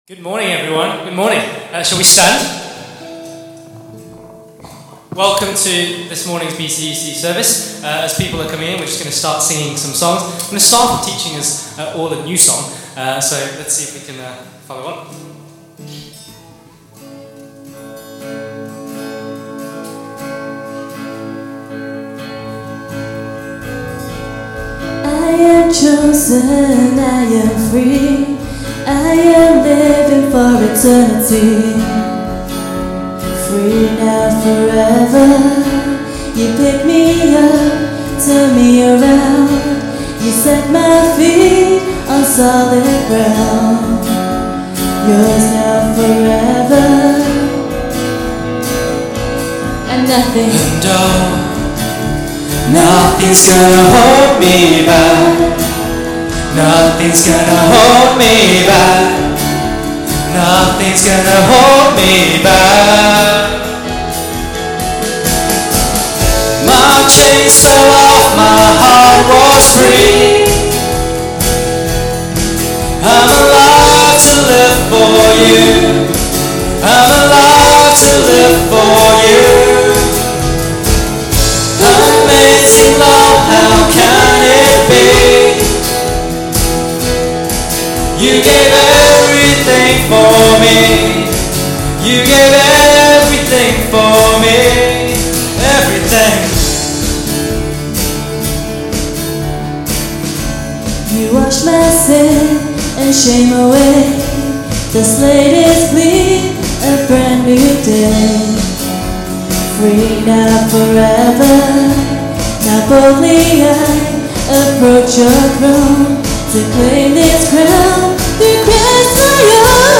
September 28 – Worship